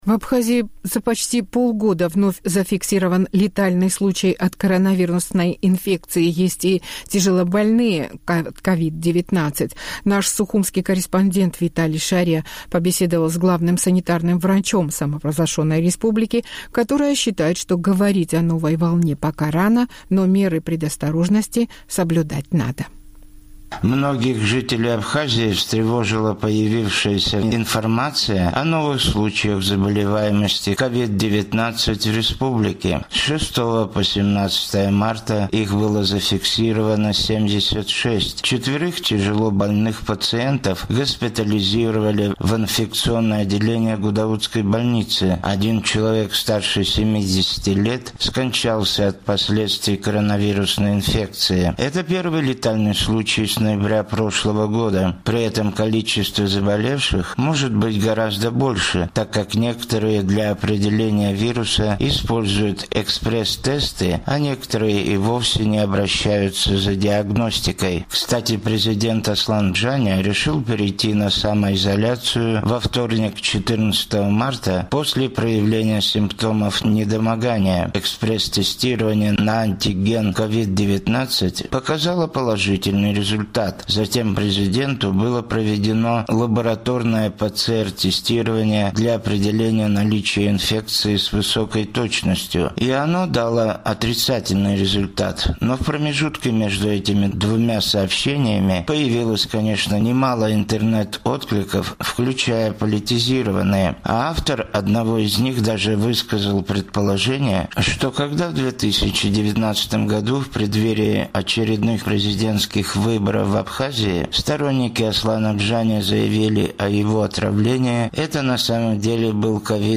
«Эхо Кавказа» побеседовало с главным санитарным врачом республики Аллой Беляевой, которая считает, что говорить о новой волне пока рано, но меры предосторожности соблюдать стоит.